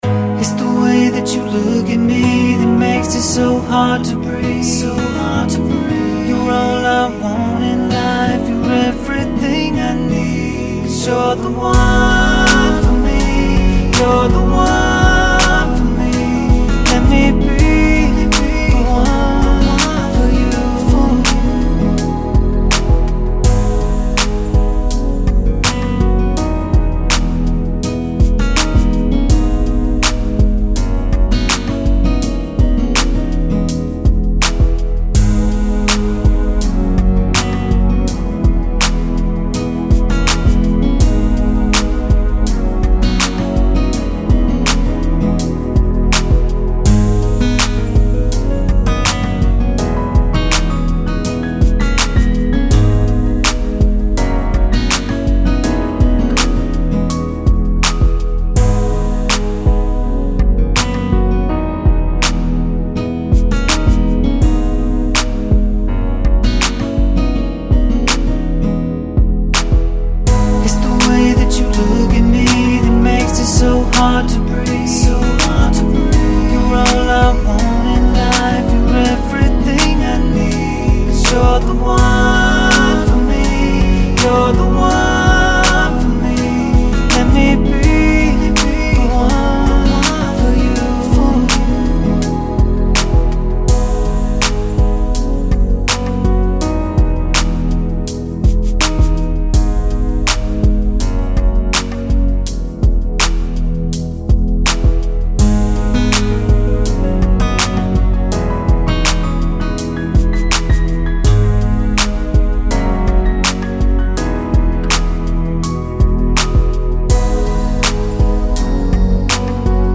R&B beat with a hook
Soft and sweet beat with synths, pianos, and strings.
91 BPM.